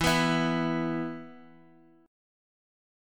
E5 Chord
Listen to E5 strummed